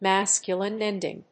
アクセントmásculine énding